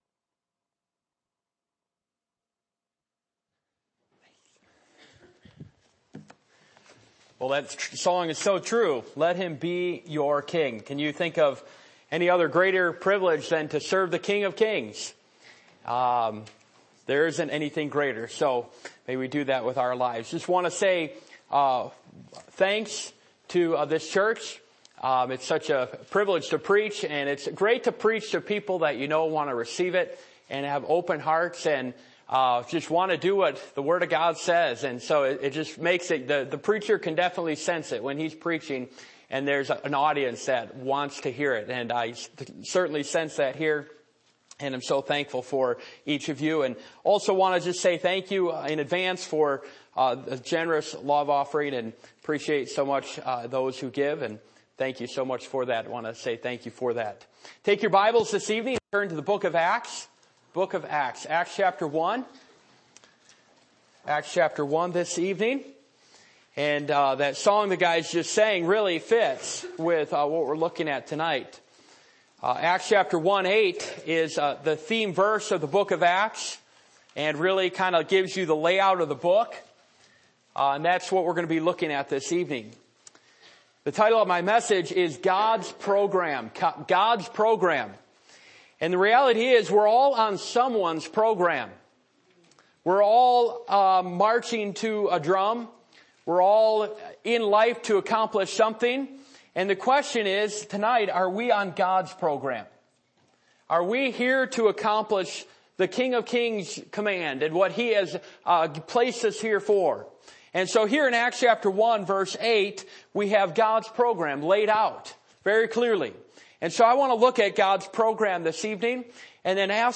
Passage: Acts 1:1-26 Service Type: Revival Meetings